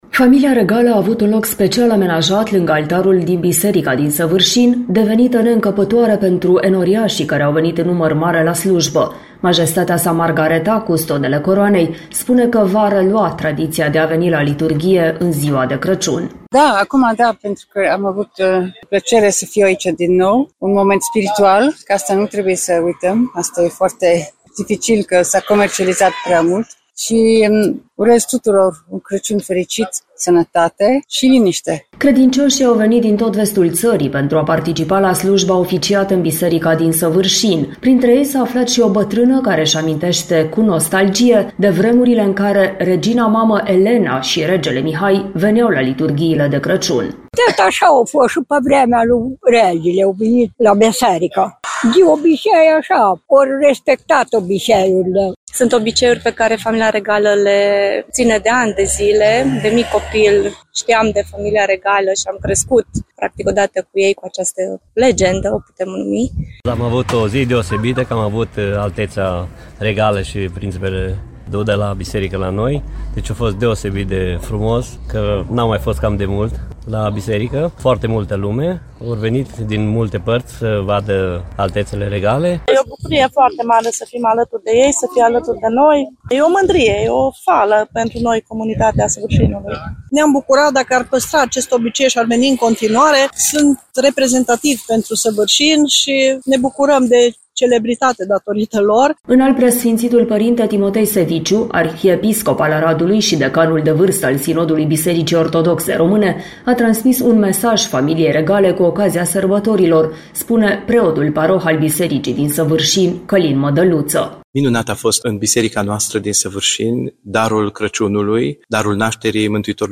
Primarul din Săvârșin, Ioan Vodicean, explică motivul pentru care Familia Regală a absentat, în ultimii ani, de la slujba de Crăciun oficiată în biserica din localitate.